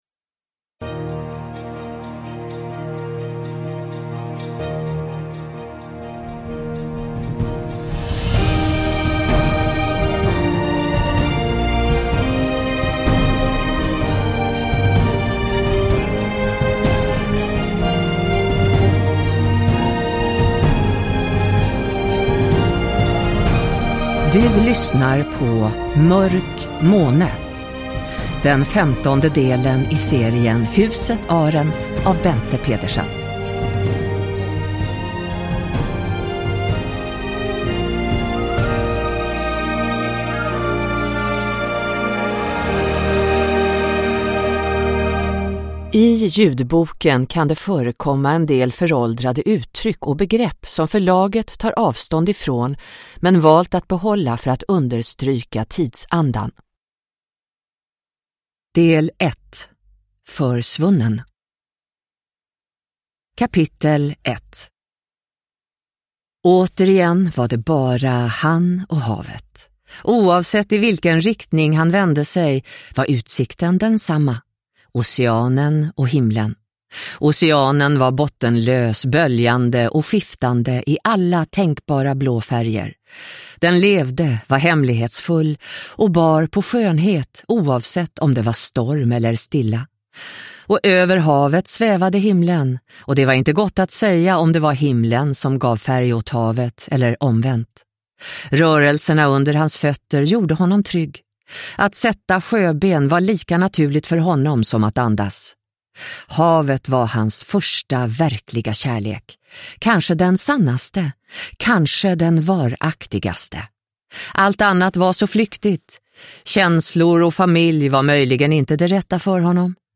Mörk måne – Ljudbok – Laddas ner